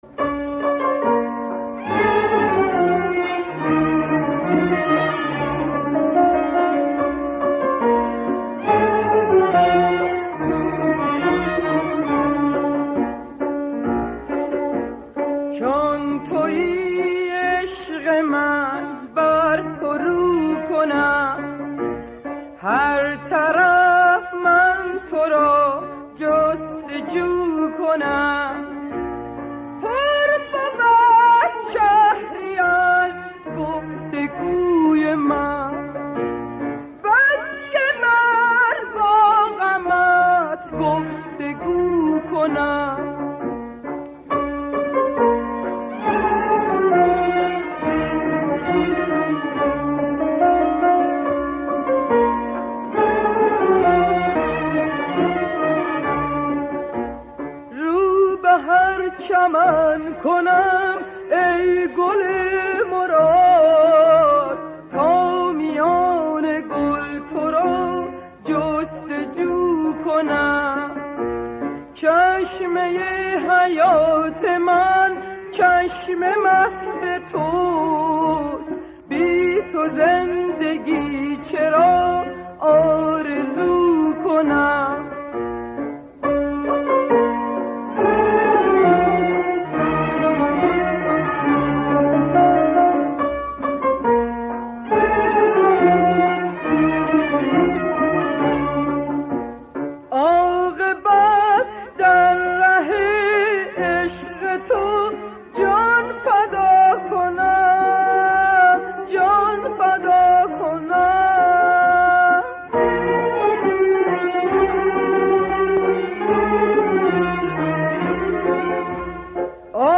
در مقام سه گاه